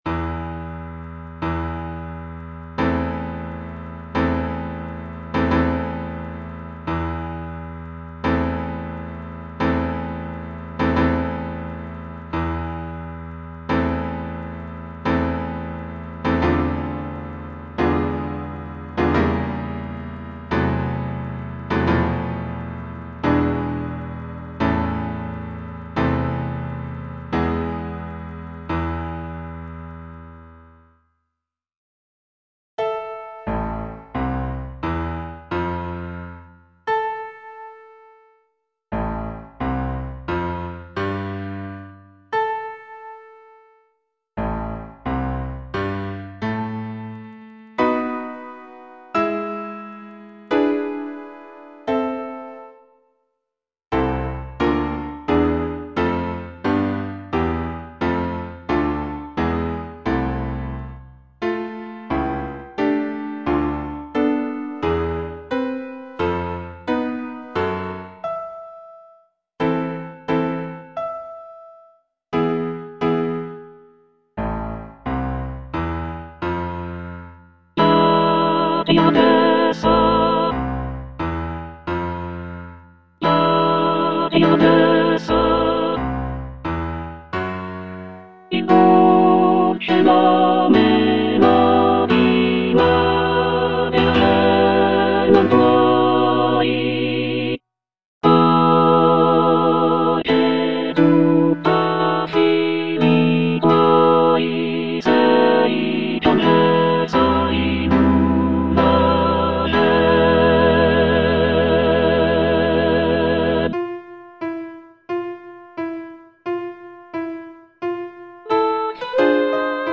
G. VERDI - CORO DI PROFUGHI SCOZZESI FROM "MACBETH" (All voices) Ads stop: auto-stop